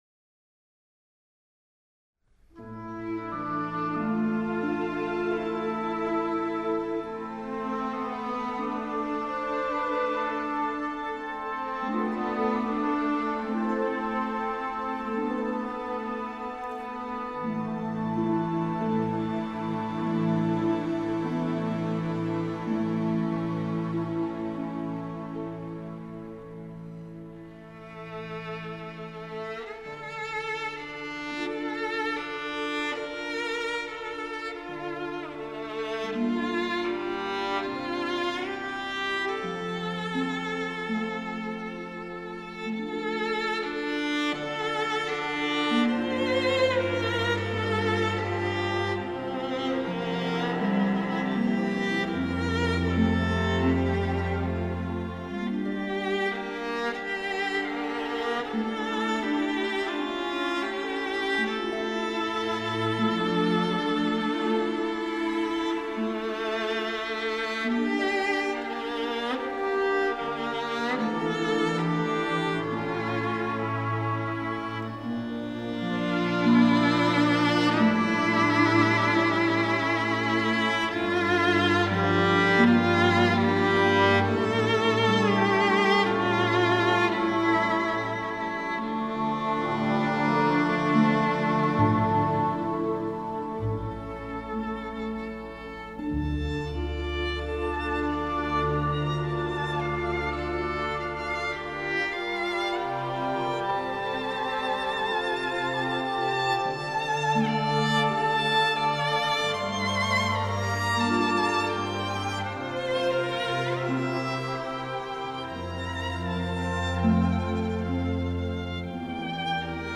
موسیقی بی کلام
موسیقی متن فیلم